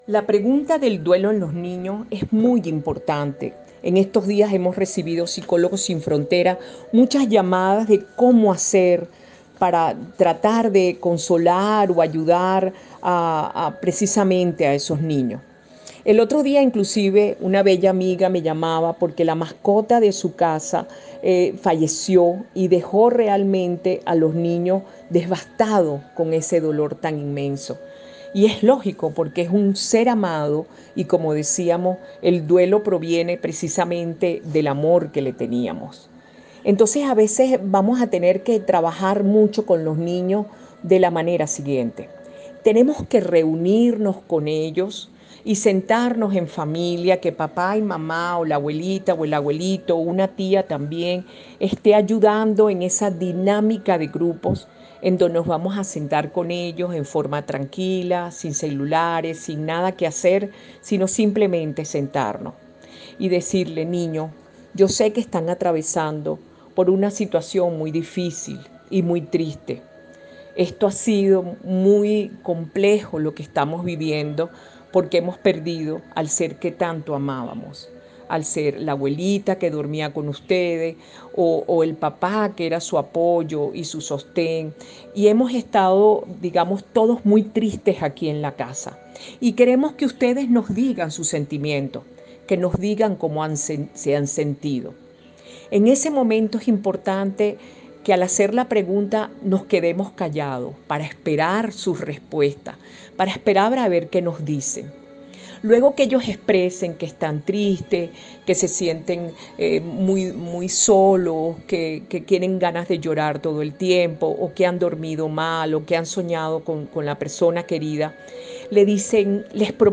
Ronda de preguntas